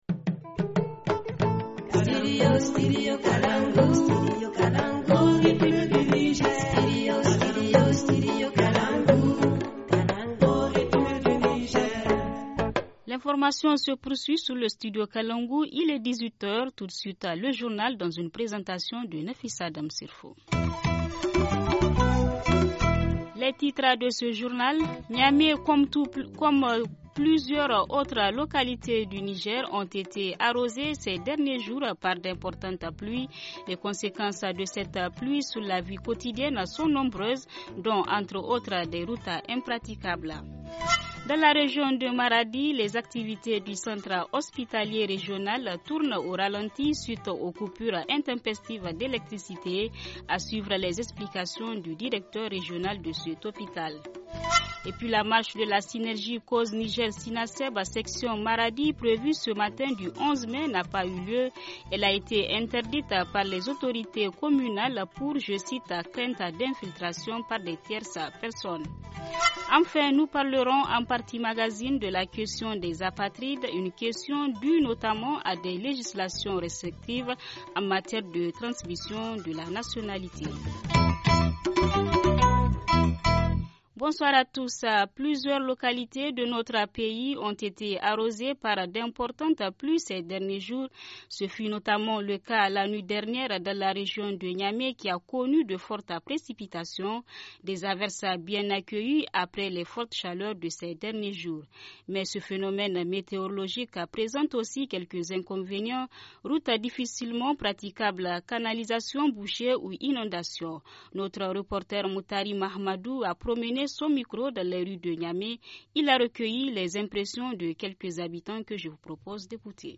Journal en français